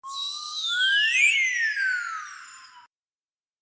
Slide Whistle
Slide Whistle is a free sfx sound effect available for download in MP3 format.
Slide Whistle.mp3